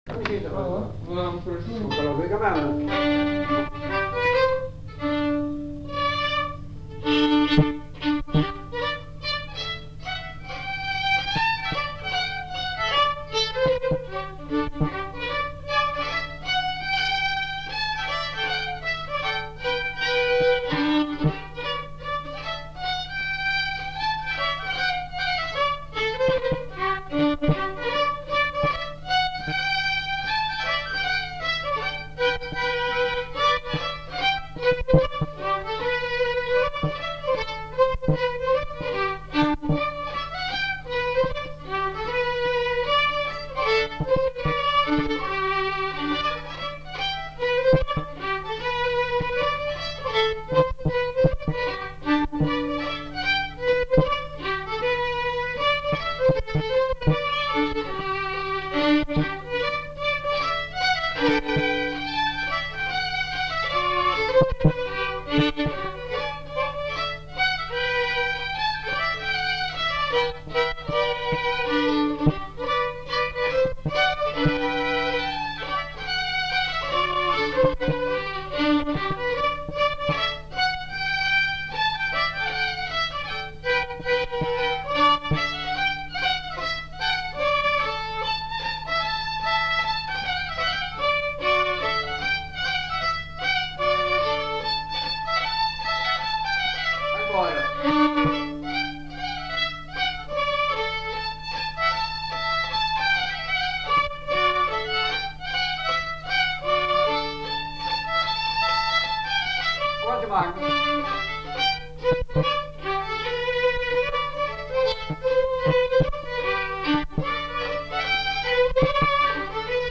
Lieu : Casteljaloux
Genre : morceau instrumental
Instrument de musique : violon
Danse : scottish
Notes consultables : 2 violons.